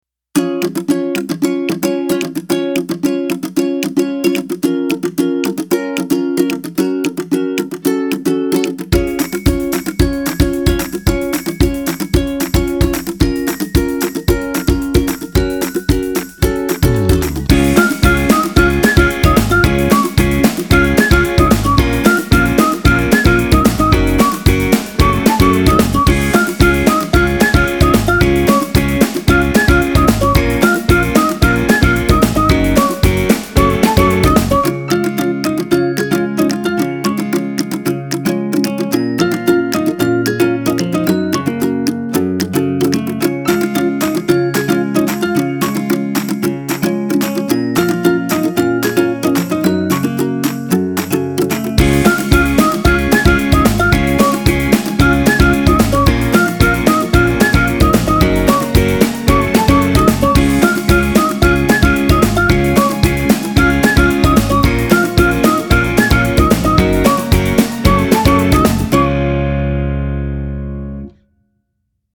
one of the most energetic and popular tracks